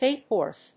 /héɪpɚθ(米国英語), héɪpəθ(英国英語)/